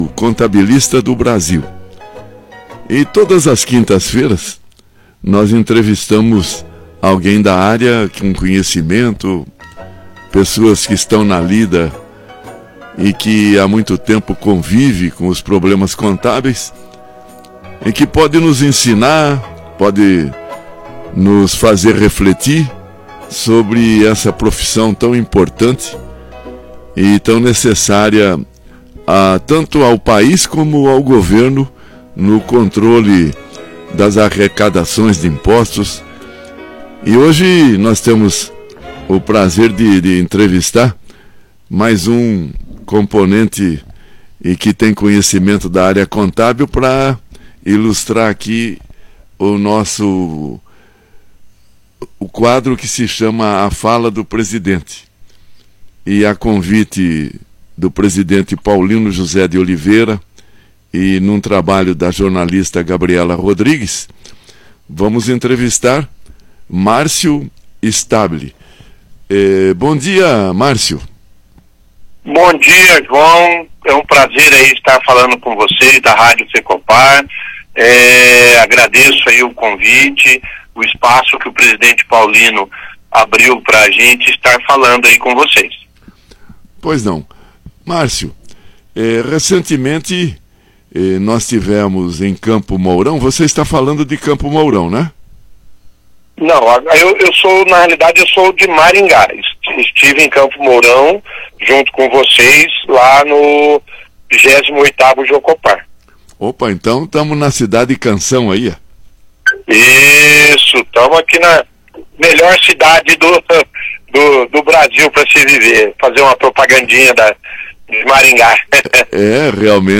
Há mais de um ano, o programa A Fala do Presidente voltou ao ar toda a quinta-feira, a partir das 10h10. Ao todo, já foram mais de 40 programas com a participação dos representantes dos Fecopar, dos Sindicatos dos Contabilistas do Paraná, das empresas parceiras e também de entidades parceiras para compartilharem suas experiências na carreira.